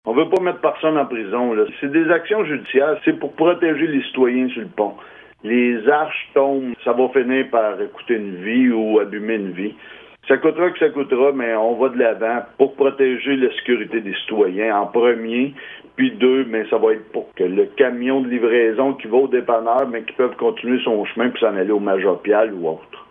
Bouchette a récemment fait un pas de plus afin que le ministère des Transports du Québec rehausse le gabarit du pont de fer. Le conseil a octroyé un mandat à la firme Cain Lamarre afin d’entreprendre un recours judiciaire. Comme l’explique le maire, Steve Lefebvre, l’objectif de la démarche est d’assurer la sécurité des citoyens ainsi que la circulation des véhicules de l’autre côté de la rive :